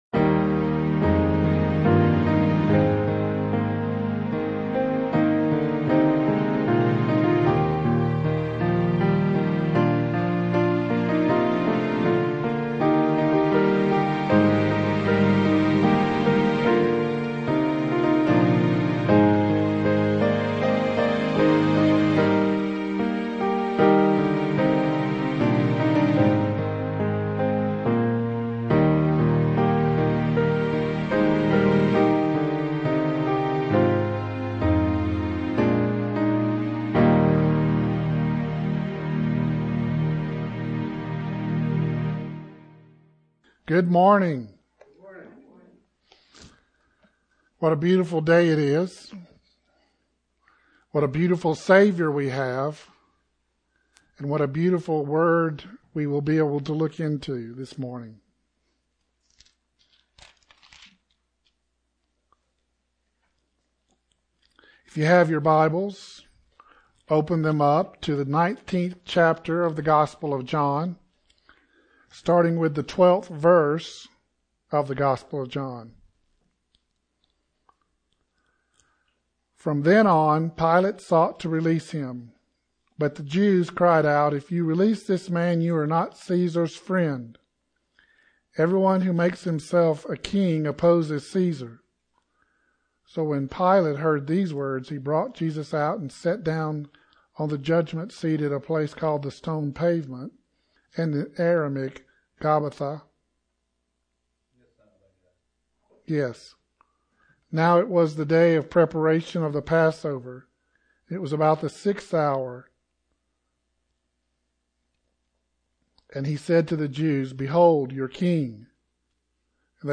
Sermon - Who Is Your King?